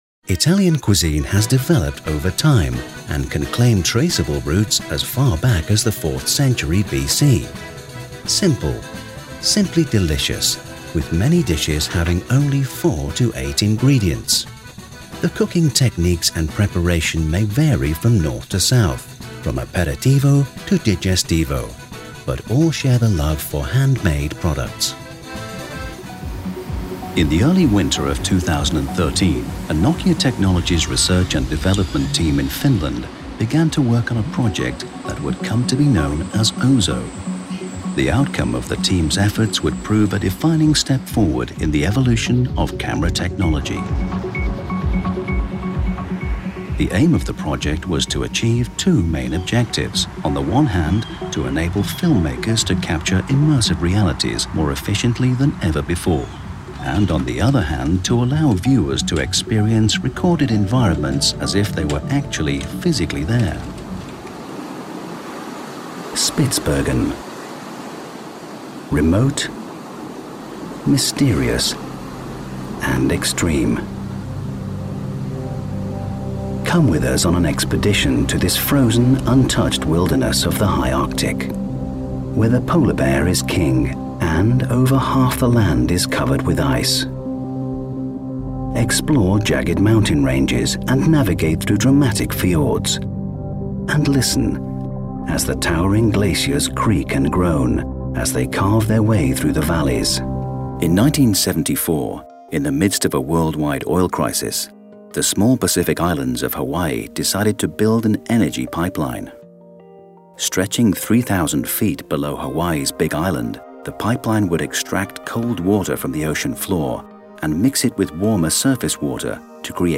Warm, Reassuring, Versatile, Engaging and Experienced British Voiceover Artist.
britisch
Sprechprobe: Sonstiges (Muttersprache):
exlWarm, reassuring, distinctive and engaging voice in demand for Commercials, Corporates, Cartoons, Documentaries, E-Learning, On-Hold, Animations and more.